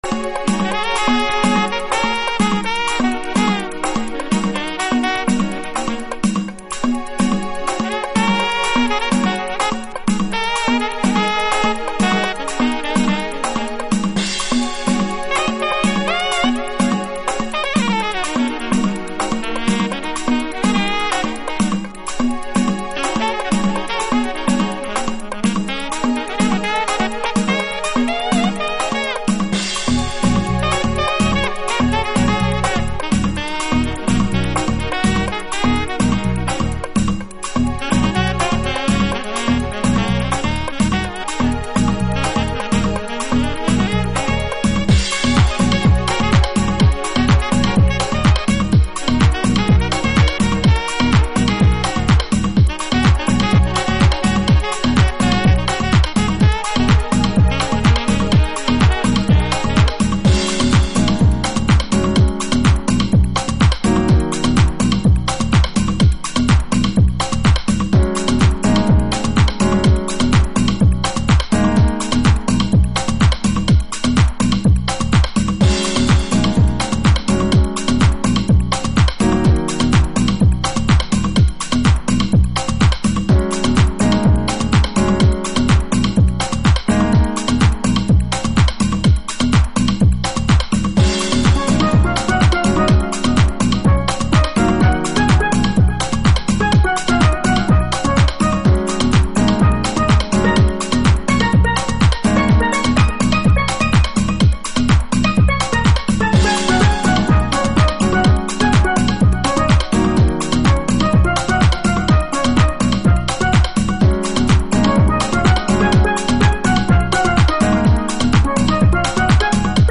House / Techno
パーカッション、スティールドラムはアコースティックでクオンタイズされたビートに独特のウネリを与えています。